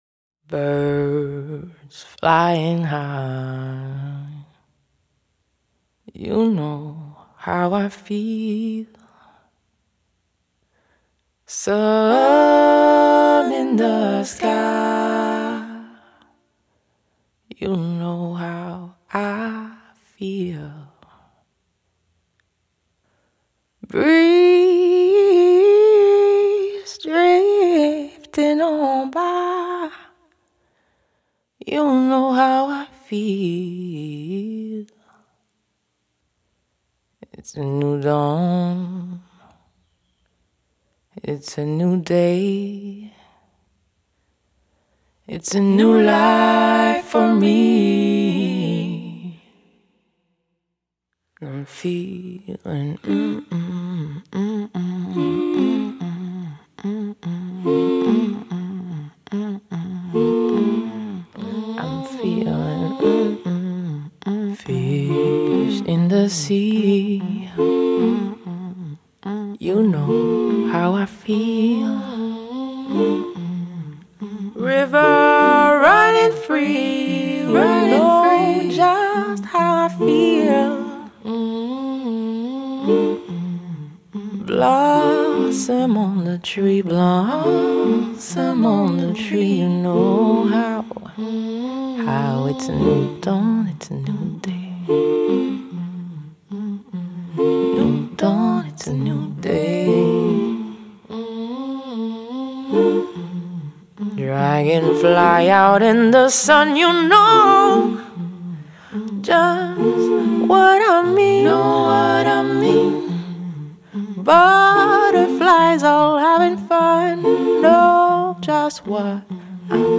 Tags2018 Canada R&B soul